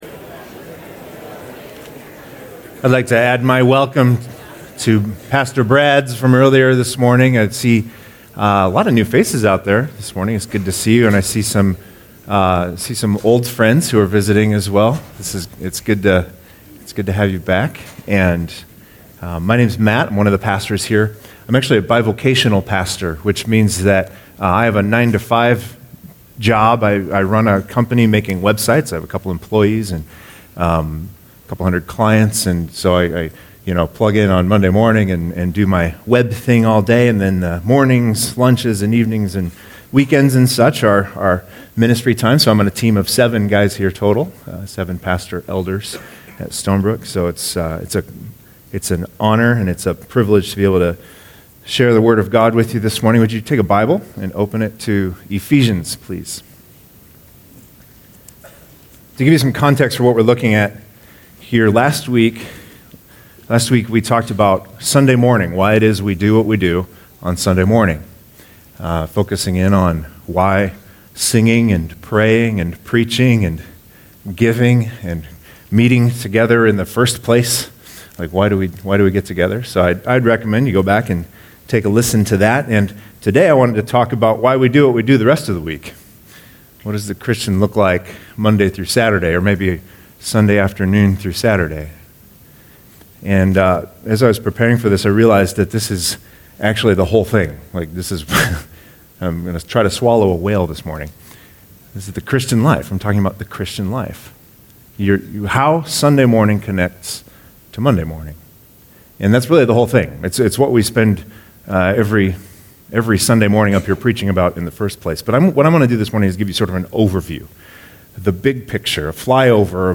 2019 File Download Worship-scattered-manuscript-w-questions.pdf Stay up to date with “ Stonebrook Church Sermons Podcast ”